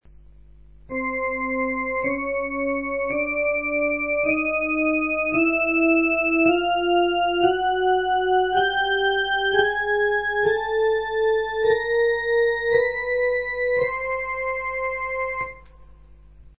Cette gamme procède par demi-tons successifs.